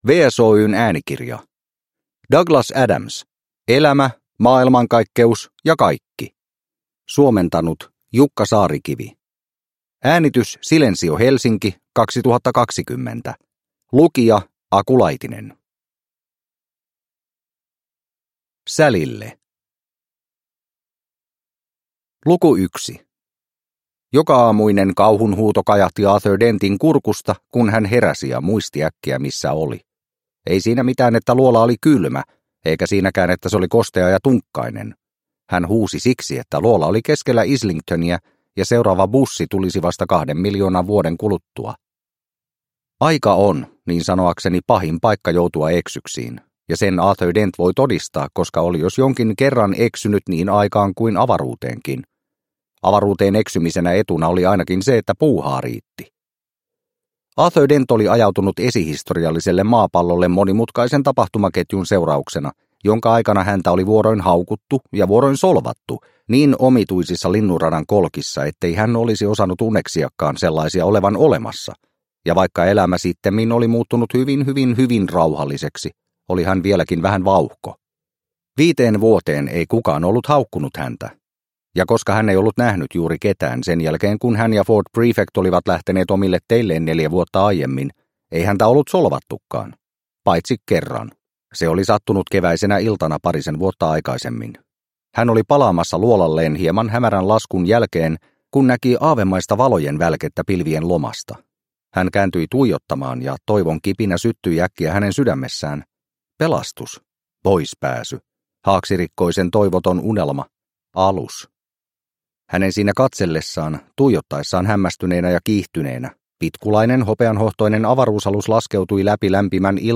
Elämä, maailmankaikkeus – ja kaikki – Ljudbok – Laddas ner